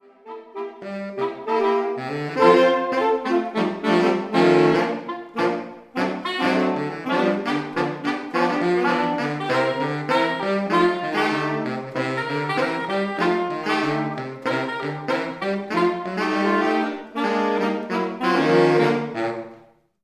Jazz & swing
The traditional home of the saxophone, jazz has a wealth of fantastic repertoire for sax quartet.